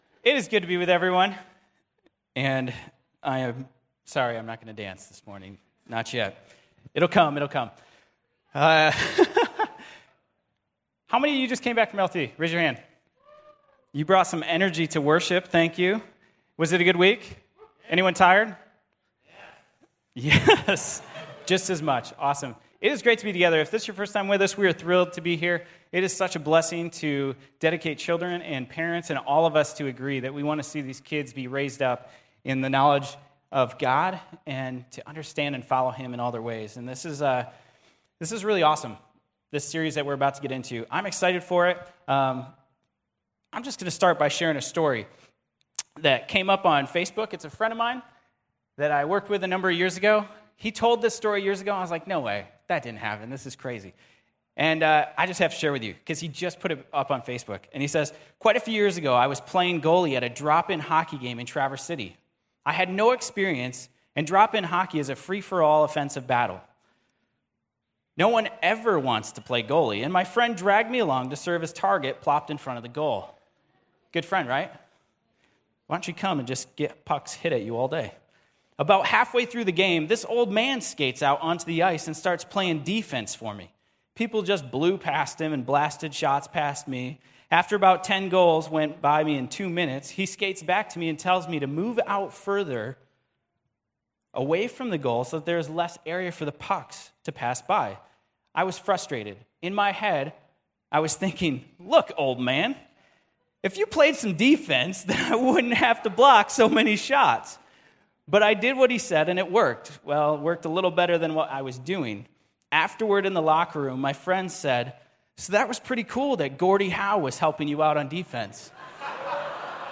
Audio Sermon Save Audio https